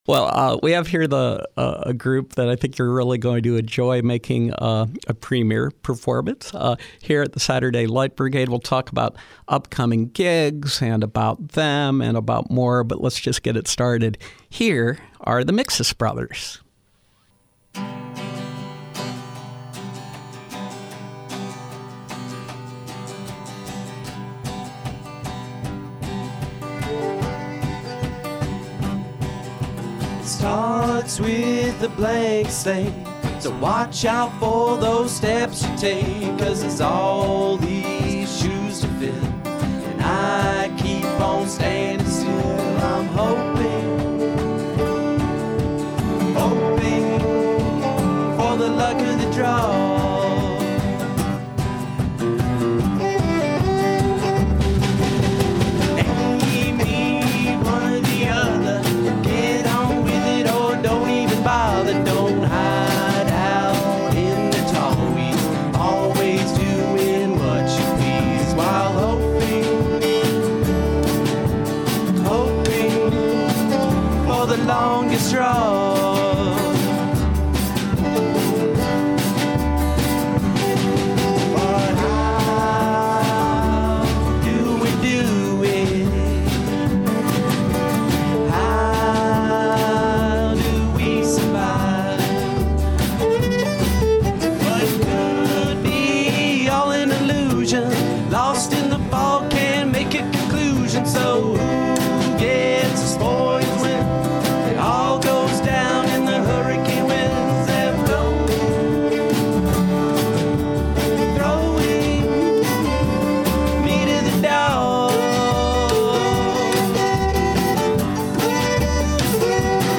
Live acoustic music